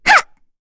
Frey-Vox_Attack4.wav